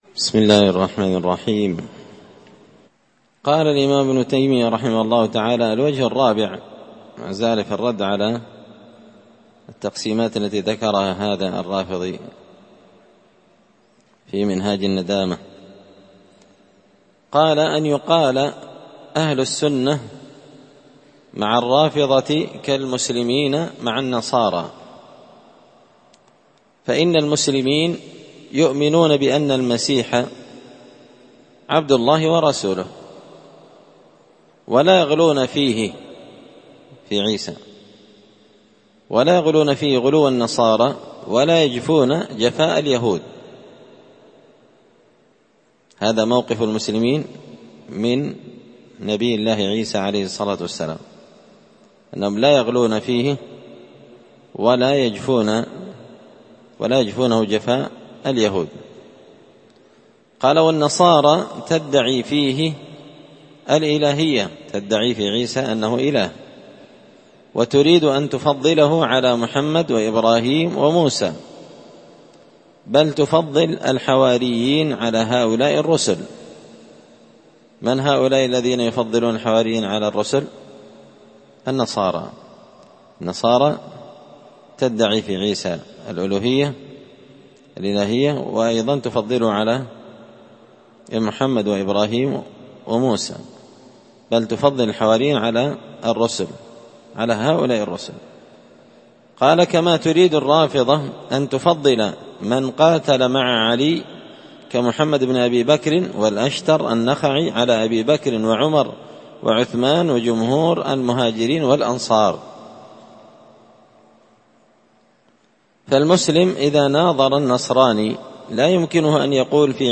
الجمعة 25 شعبان 1444 هــــ | الدروس، دروس الردود، مختصر منهاج السنة النبوية لشيخ الإسلام ابن تيمية | شارك بتعليقك | 7 المشاهدات